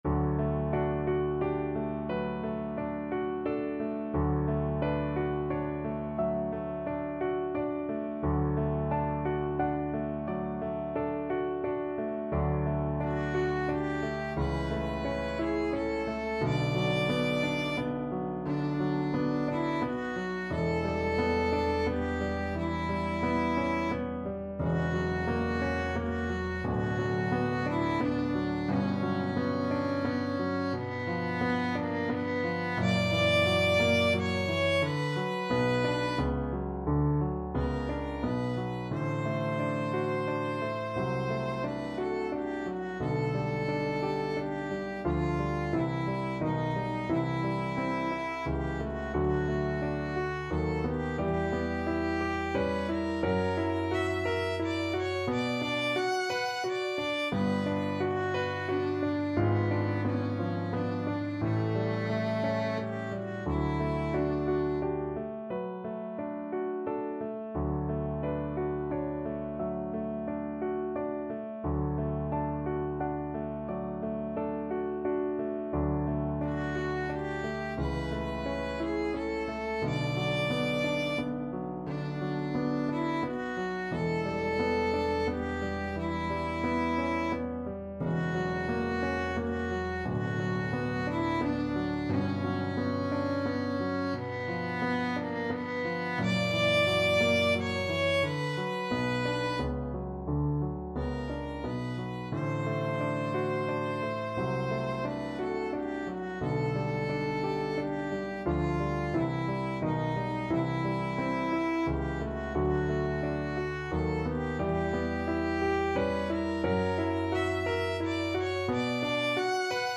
Classical Fanny Mendelssohn Die Mainacht from 6 Lieder, Op.9 Violin version
D major (Sounding Pitch) (View more D major Music for Violin )
6/4 (View more 6/4 Music)
~ = 88 Andante
Classical (View more Classical Violin Music)